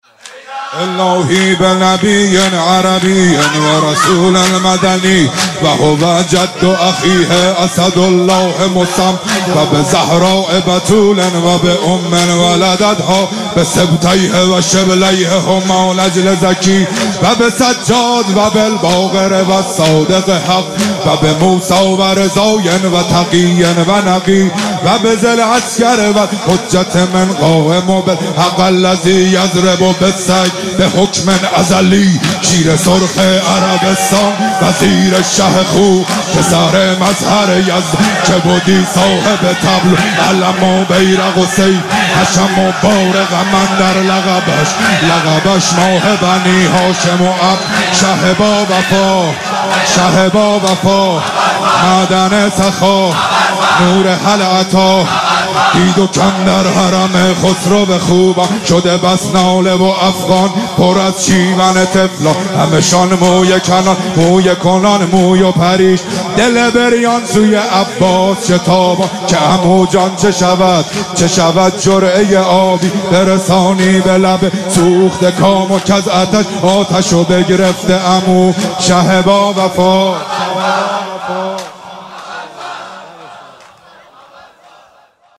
مراسم ایام فاطمیه دوم